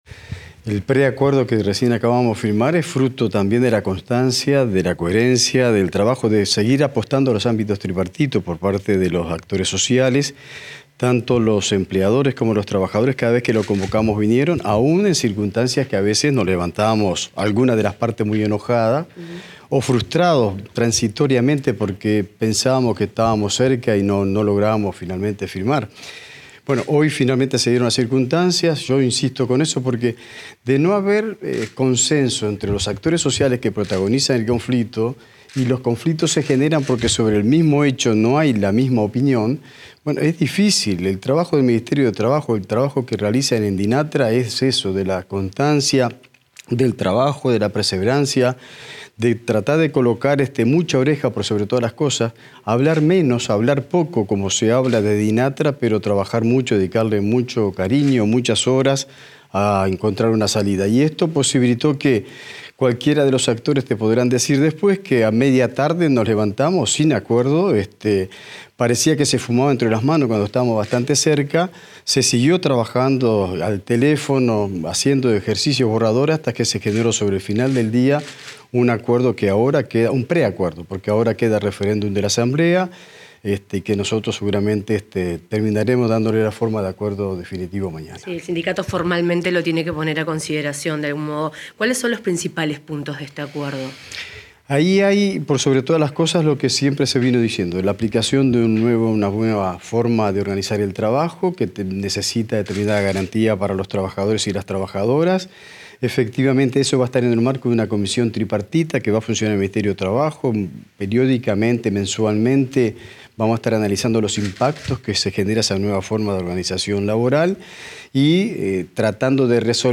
Declaraciones del ministro de Trabajo, Juan Castillo
Tras la firma del acuerdo entre la empresa Katoen Natie, el Gobierno y el Sindicato Único Portuario, el ministro de Trabajo y Seguridad Social, Juan Castillo, dialogó con la prensa